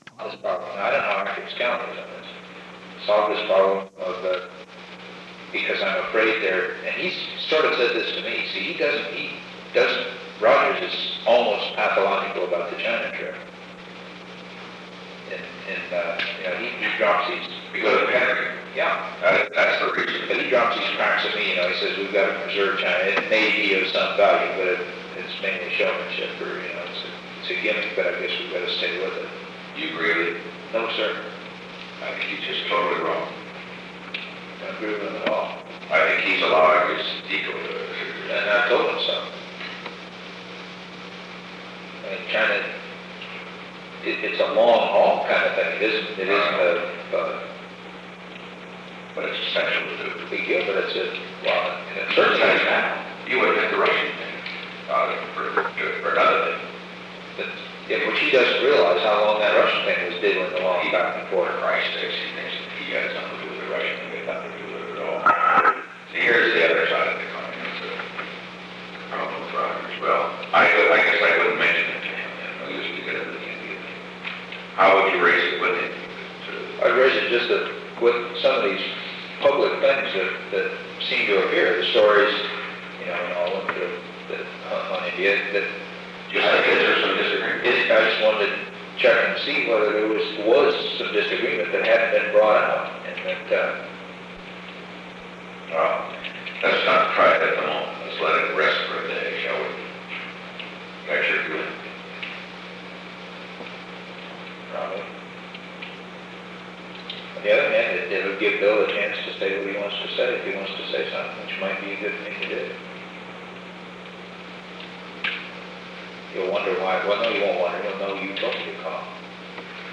On December 7, 1971, President Richard M. Nixon and H. R. ("Bob") Haldeman met in the Oval Office of the White House at an unknown time between 12:57 pm and 1:58 pm. The Oval Office taping system captured this recording, which is known as Conversation 631-001 of the White House Tapes.
[The recording begins at an unknown time while the conversation is in progress]